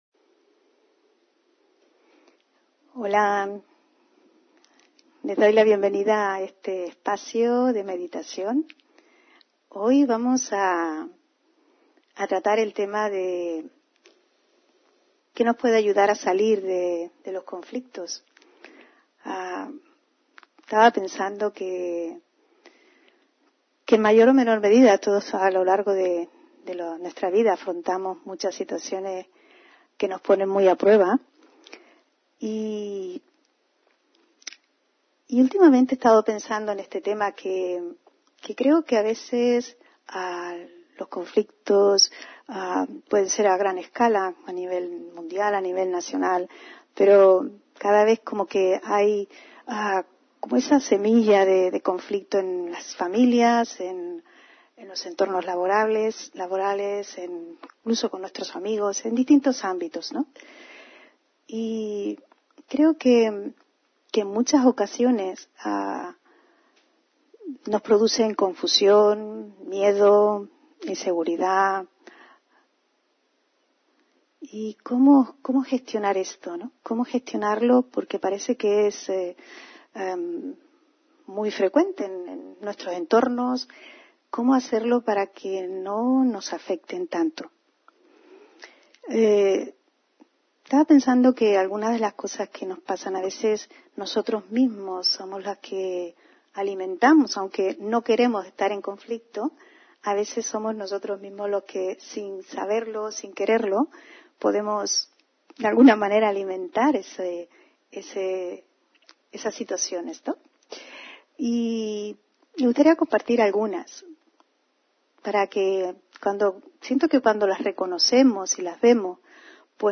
Meditación y conferencia: Saliendo de los conflictos (24 Mayo 2023)